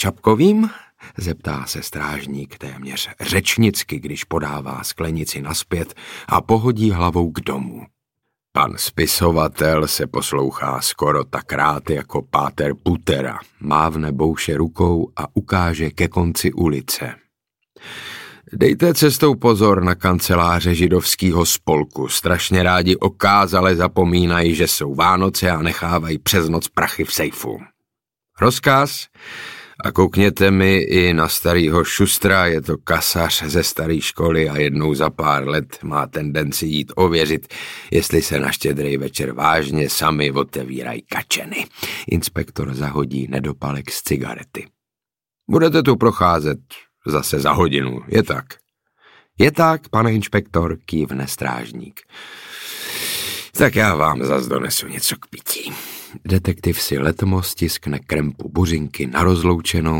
Audiobook
Read: Saša Rašilov ml.